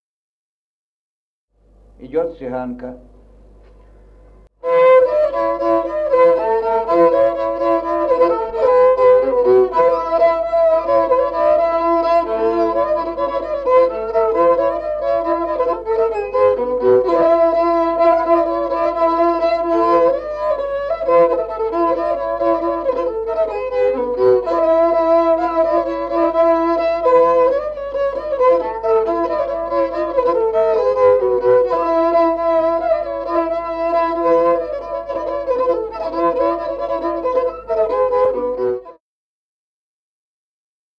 Музыкальный фольклор села Мишковка «Цыганка», репертуар скрипача.